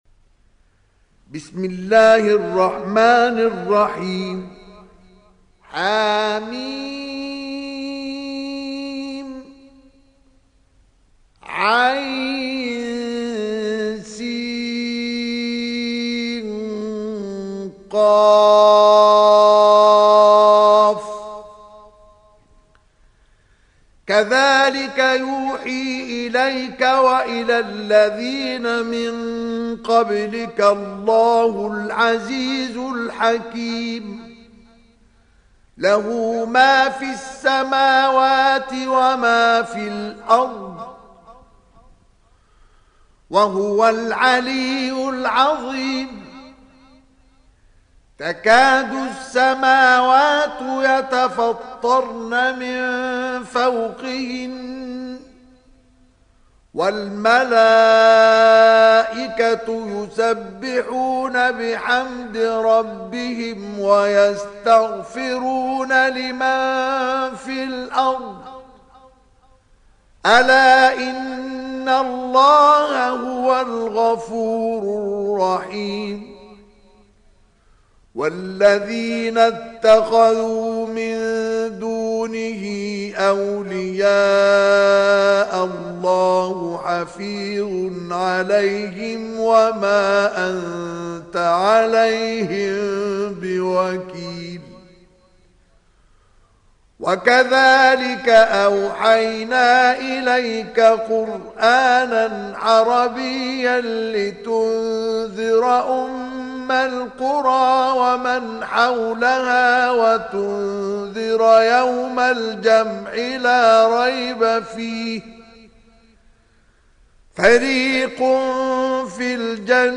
সূরা আশ-শূরা ডাউনলোড mp3 Mustafa Ismail উপন্যাস Hafs থেকে Asim, ডাউনলোড করুন এবং কুরআন শুনুন mp3 সম্পূর্ণ সরাসরি লিঙ্ক